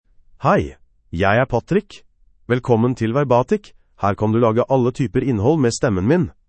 MaleNorwegian Bokmål (Norway)
Patrick — Male Norwegian Bokmål AI voice
Voice sample
Male